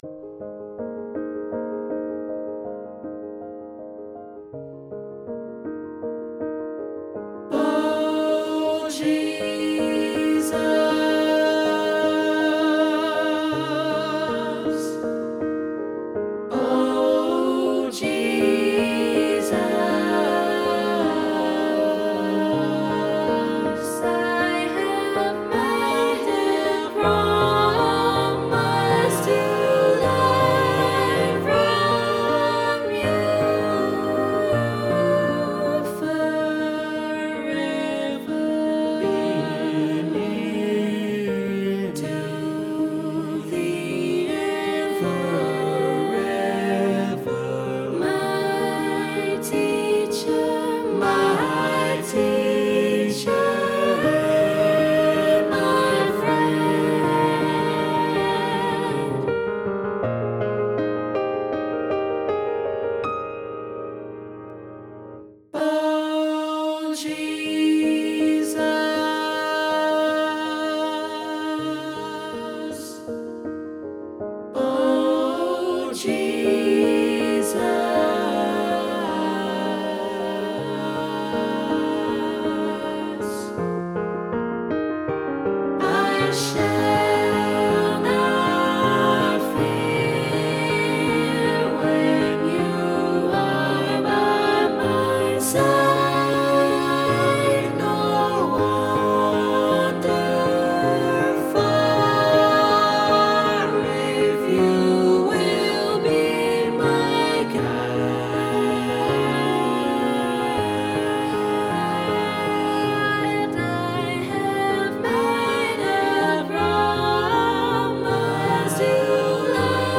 SAB + Piano
SAB, Piano